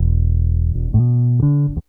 BASS 24.wav